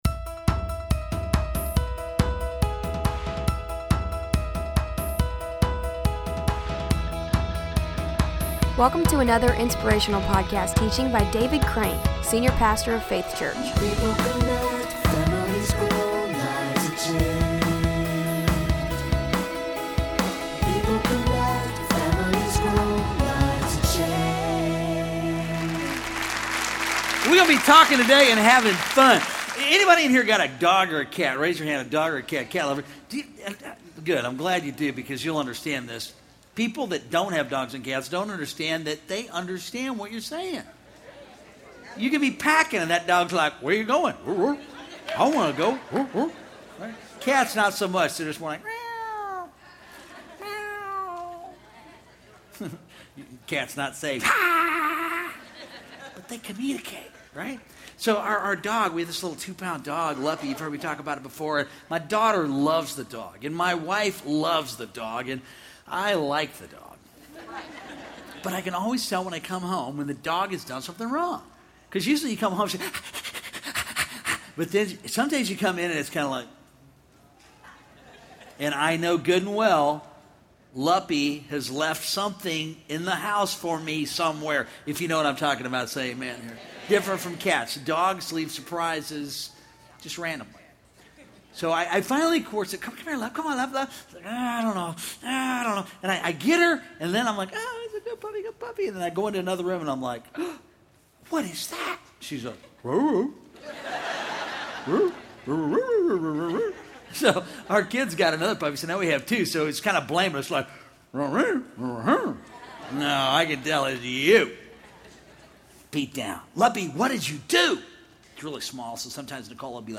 Episode from Faith Church Audio Podcast